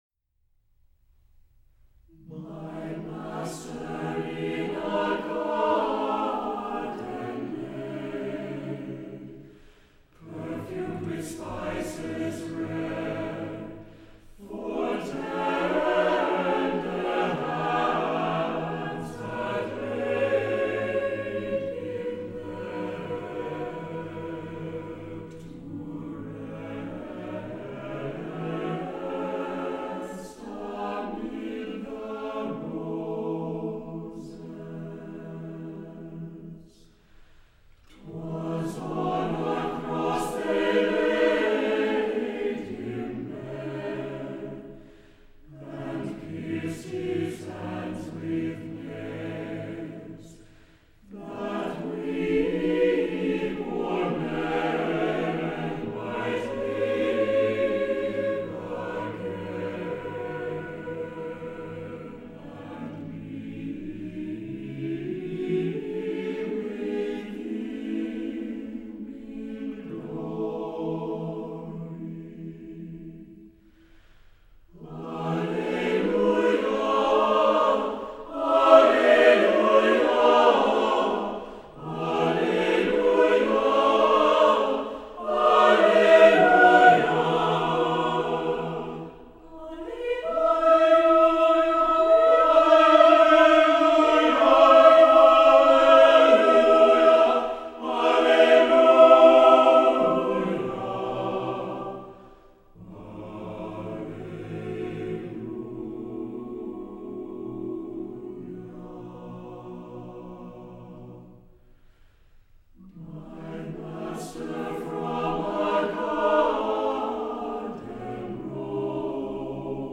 ANTHEM My Master From a Garden Rose             E. Daley
Fairlawn Avenue Chamber Choir
Soprano
Alto
Tenor
Bass